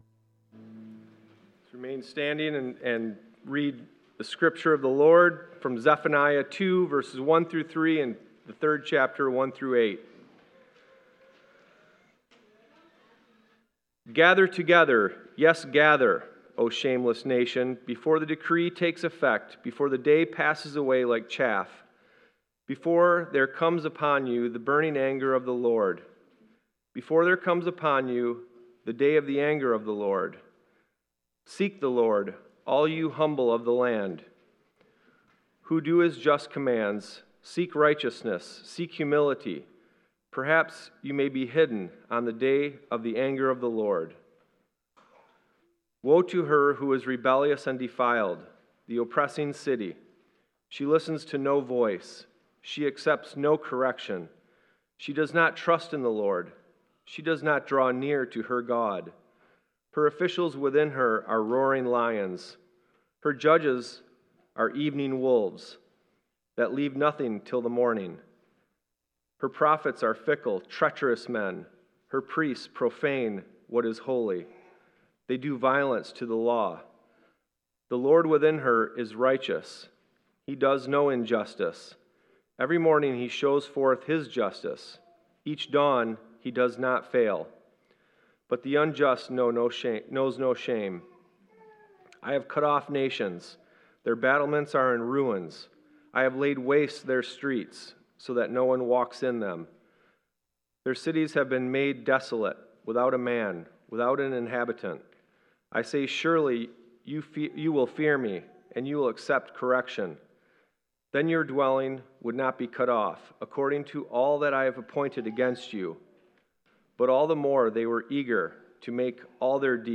5.4.25 sermon.m4a